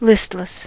listless: Wikimedia Commons US English Pronunciations
En-us-listless.WAV